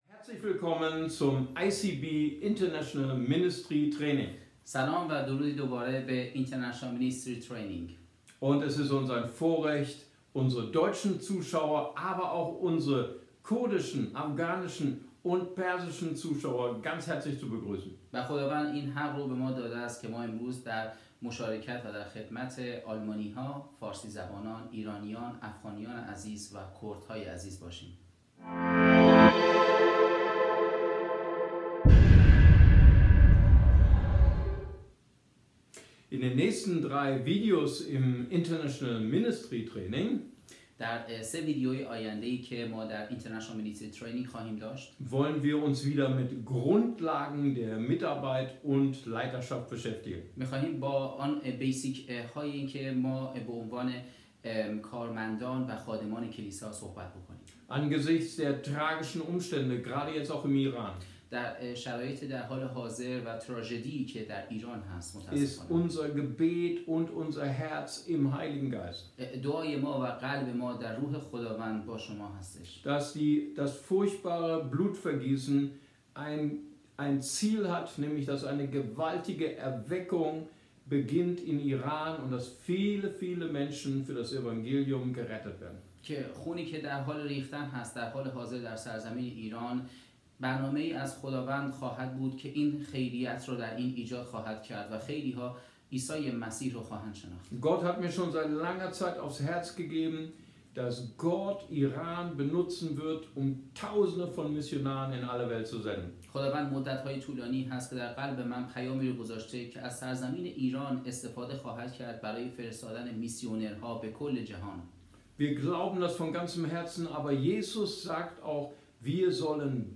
Deutsch Farsi